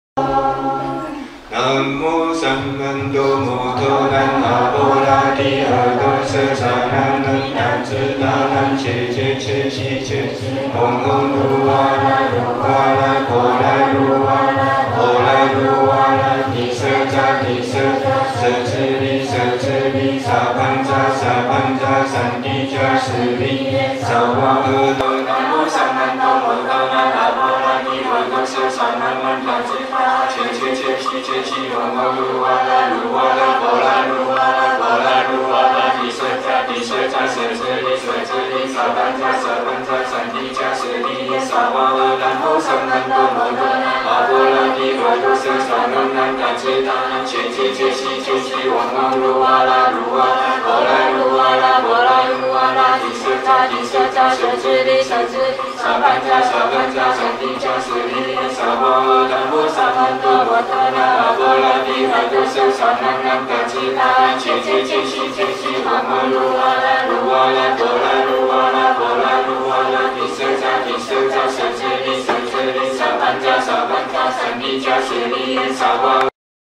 Recitation: